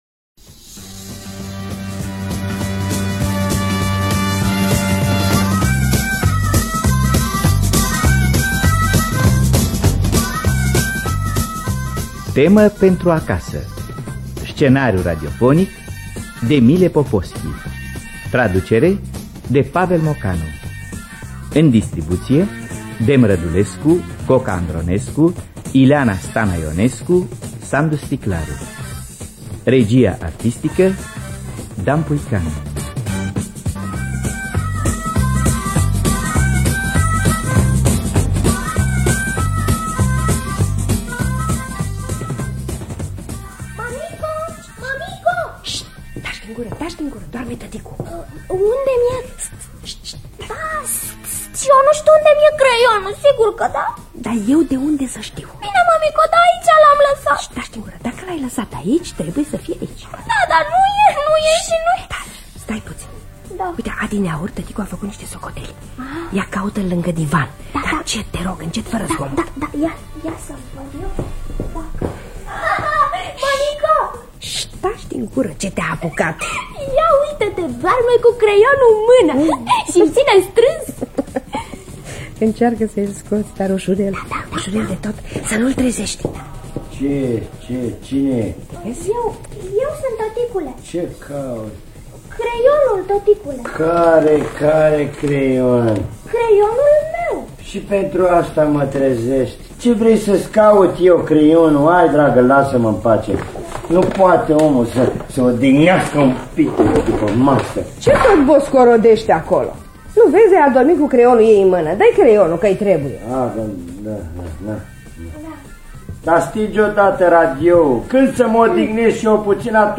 Temă pentru acasă de Mile Poposki – Teatru Radiofonic Online